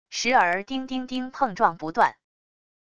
时而叮叮叮碰撞不断wav音频